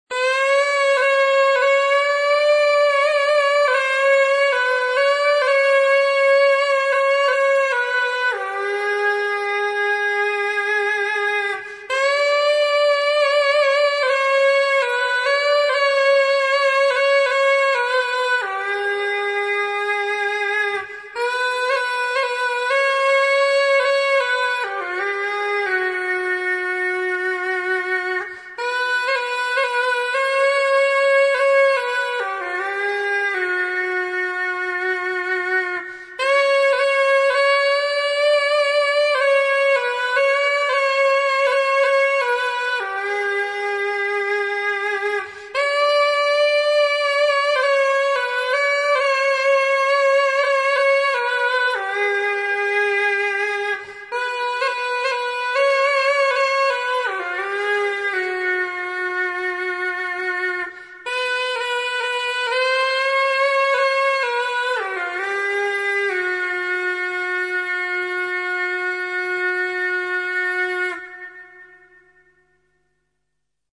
Камыс-сырнай